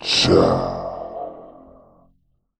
CHAAH2M.wav